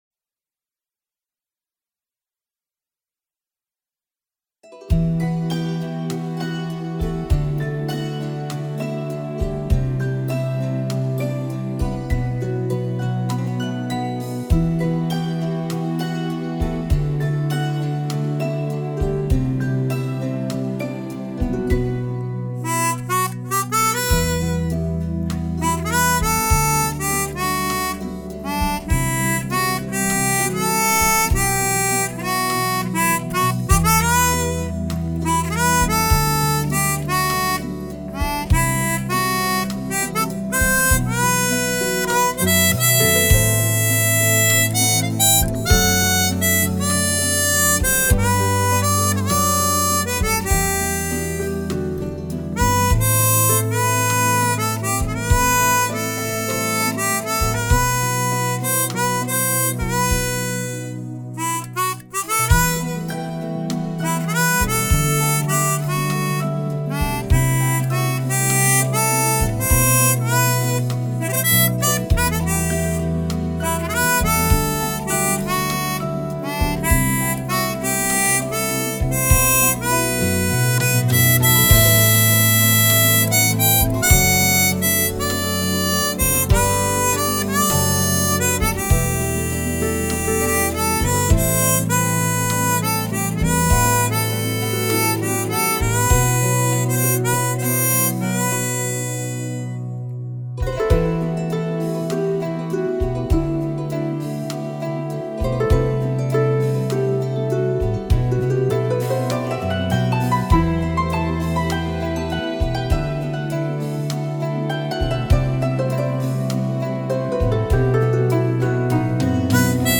Inities-toi à l'harmonica chromatique et constitues-toi un répertoire avec des titres magnifiques et émouvants